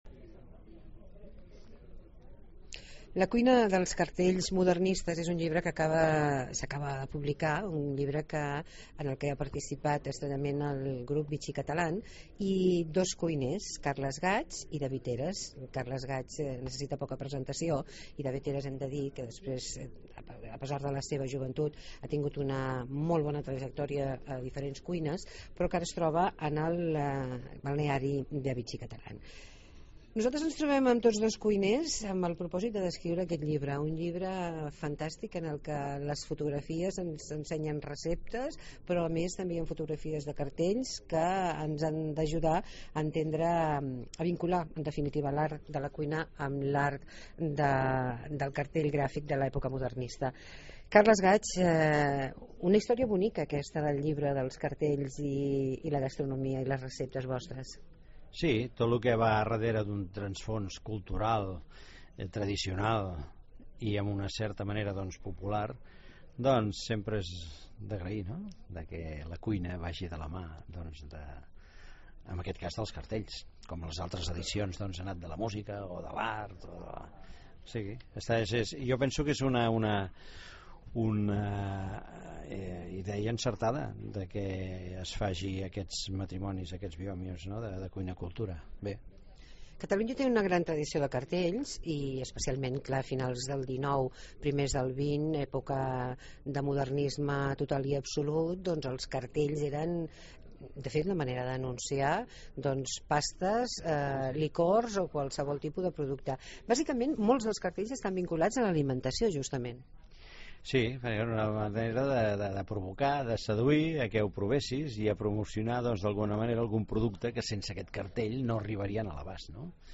Entrevista amb Carles Gaig